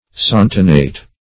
santonate - definition of santonate - synonyms, pronunciation, spelling from Free Dictionary Search Result for " santonate" : The Collaborative International Dictionary of English v.0.48: Santonate \San"to*nate\, n. (Chem.) A salt of santonic acid.